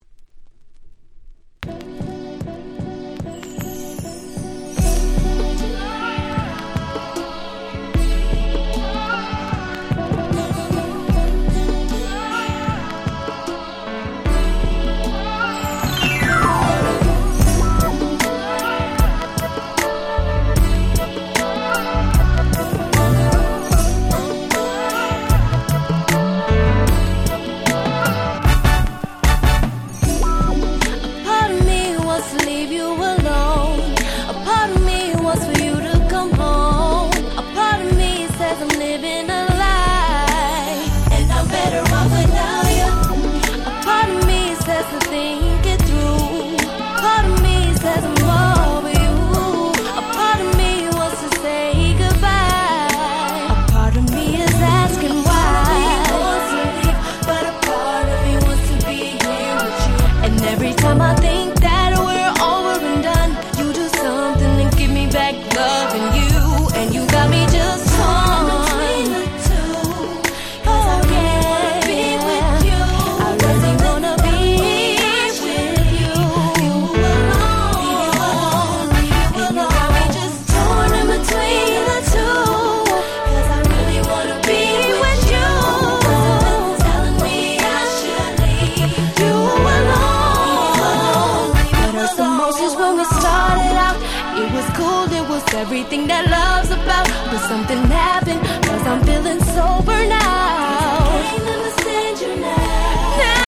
06' Super Hit R&B !!
00's キラキラ系 Slow Jam スロウジャム